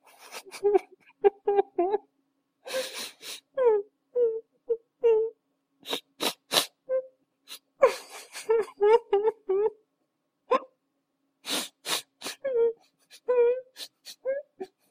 На этой странице собраны звуки момо — необычные и тревожные аудиоэффекты, которые подойдут для творческих проектов.
Звук Момо в громком плаче